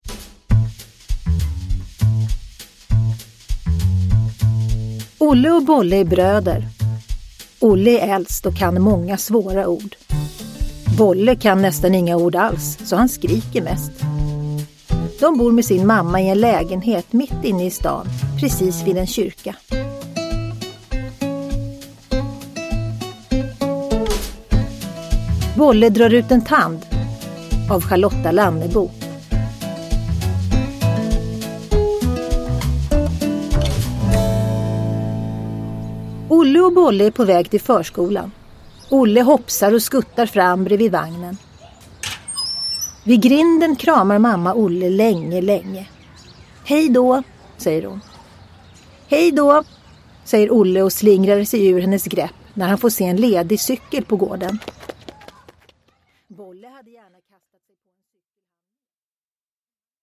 Bolle drar ut en tand – Ljudbok – Laddas ner
Uppläsare: Tova Magnusson